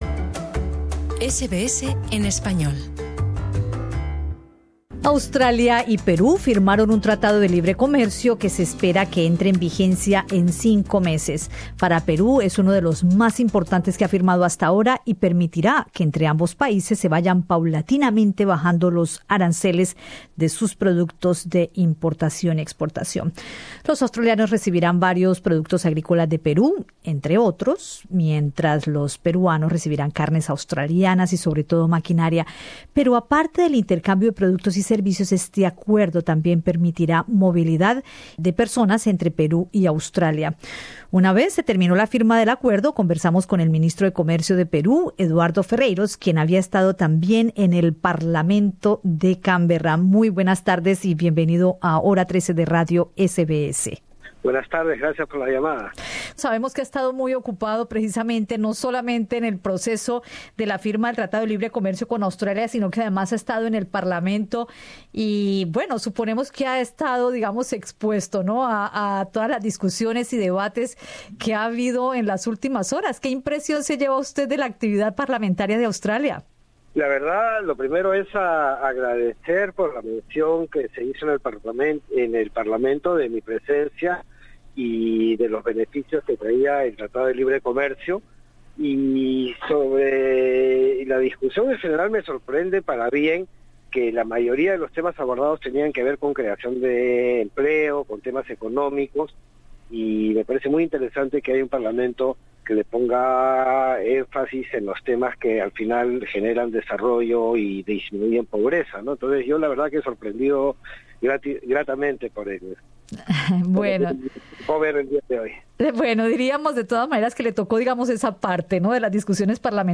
Escucha en el podcast la entrevista con el ministro de Comercio de Perú Eduardo Ferreyros , quien entregó detalles a Radio SBS sobre algunos aspectos del acuerdo.